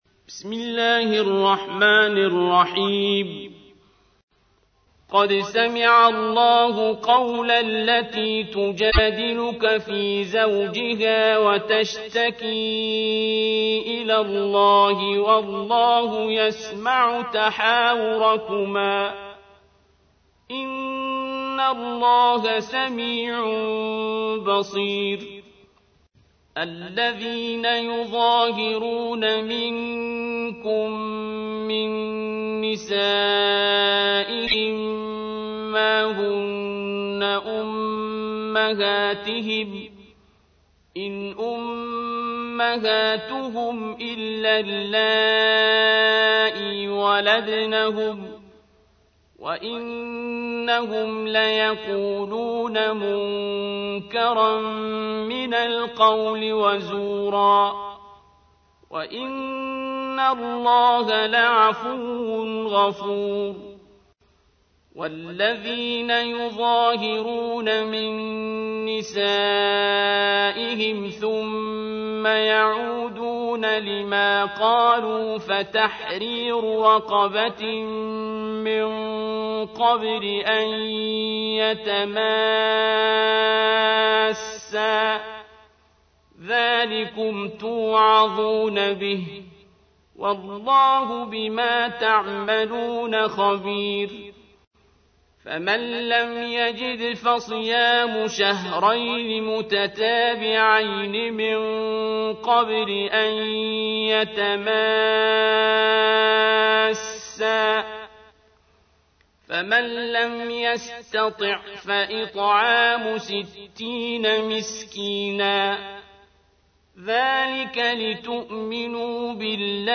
تحميل : 58. سورة المجادلة / القارئ عبد الباسط عبد الصمد / القرآن الكريم / موقع يا حسين